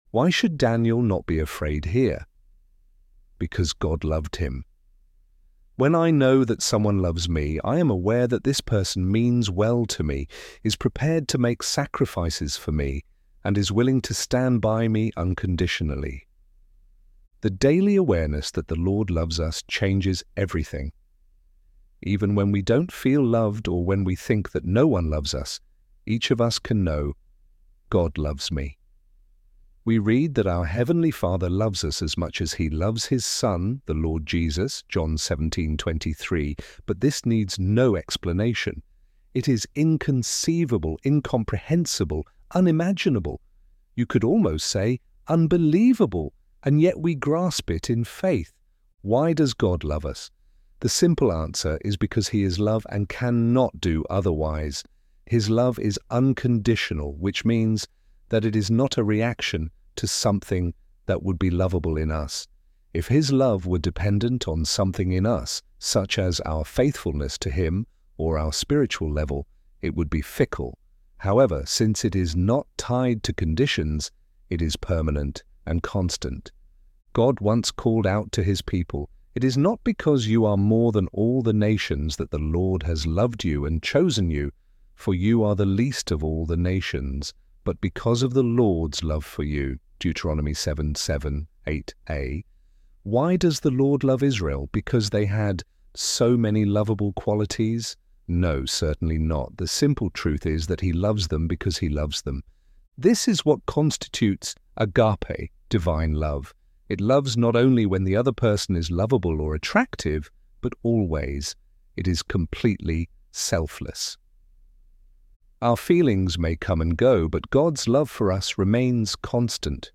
ElevenLabs_Fear_Gods_love.mp3